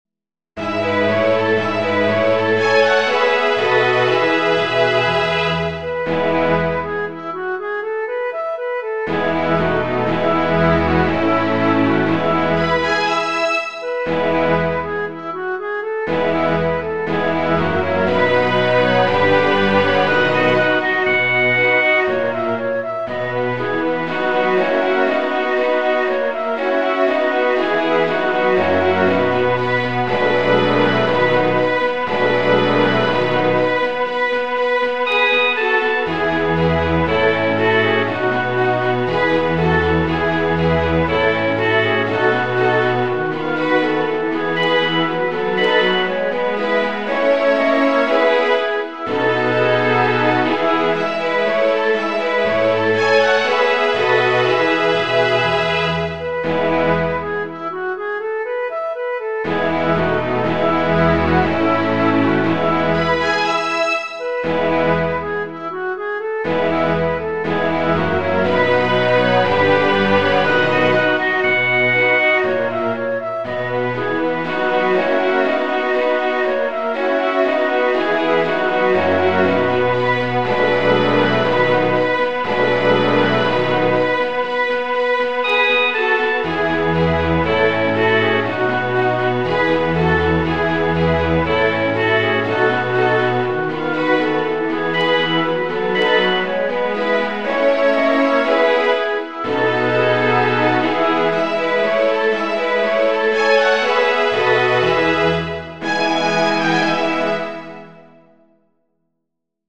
Couplets